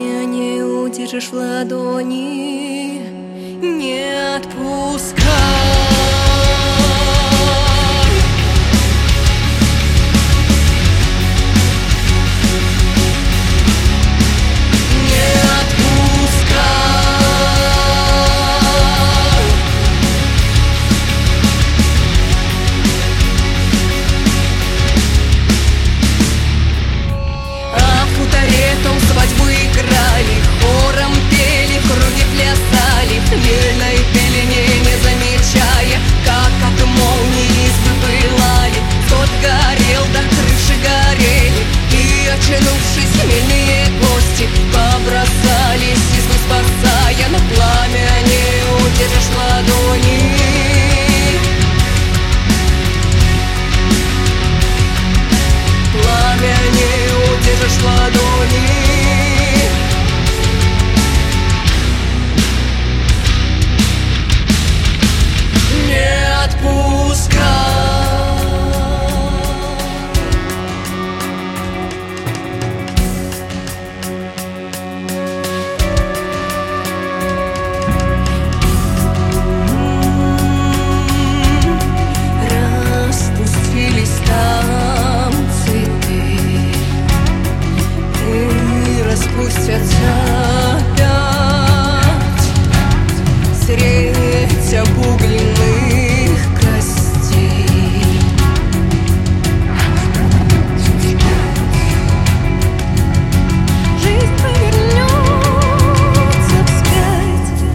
Фолк рок!